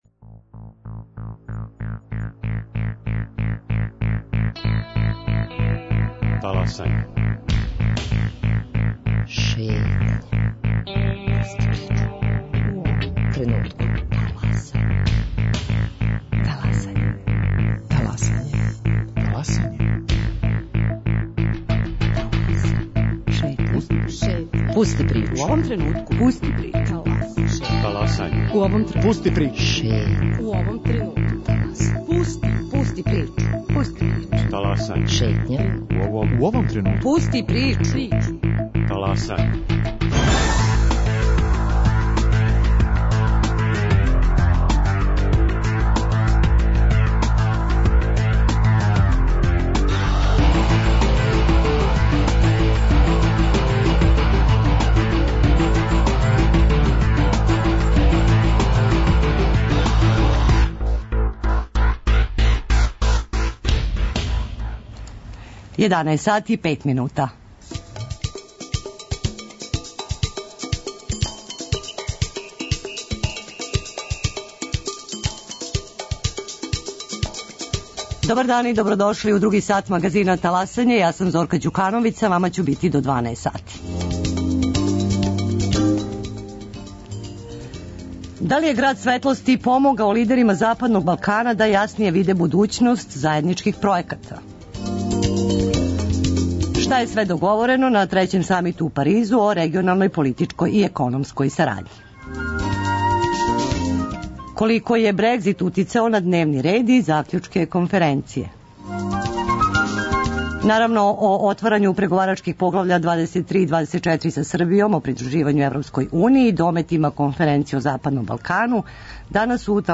О отварању преговарачких поглавља 23 и 24 са Србијом о придруживању ЕУ и дометима Конференције о Западном Балкану говоре учесници самита у Паризу и гост у студију